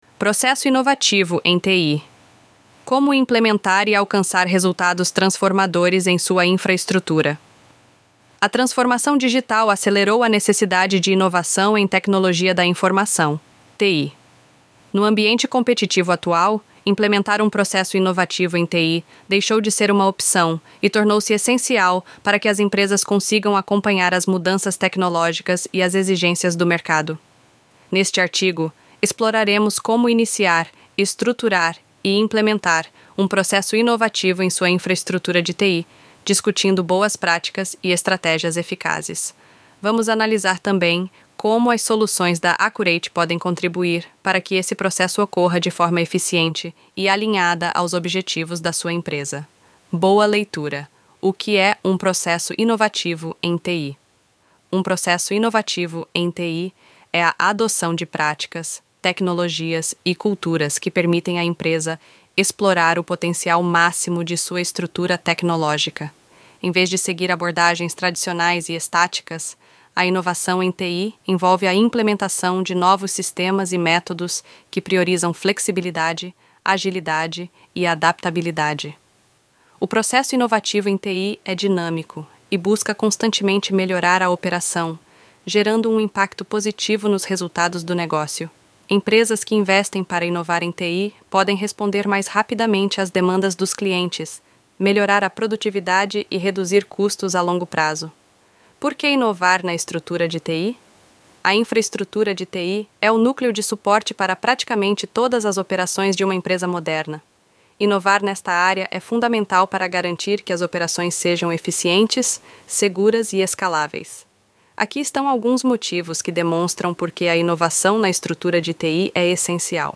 Texto-sobre-Processo-inovativo-em-TI-Narracao-Avatar-Rachel-ElevenLabs.mp3